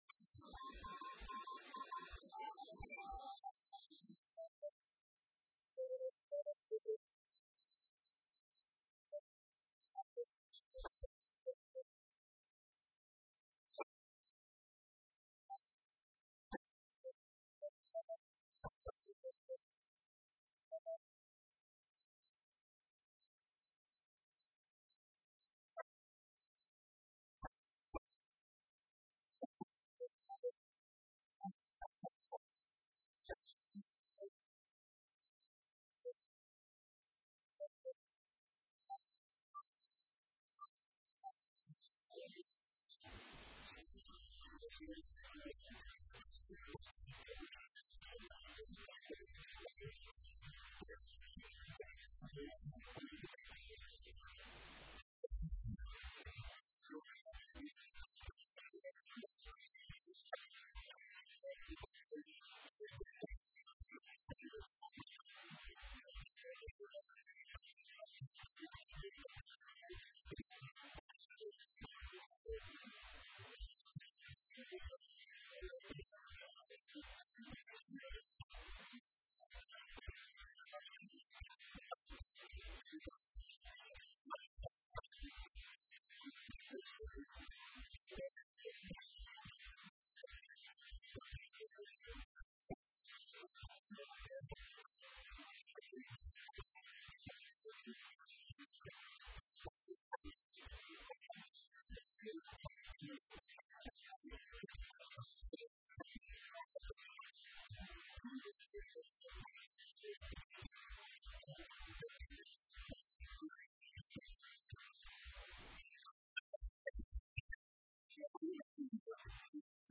Dhageyso; Warka Subax ee Radio Muqdisho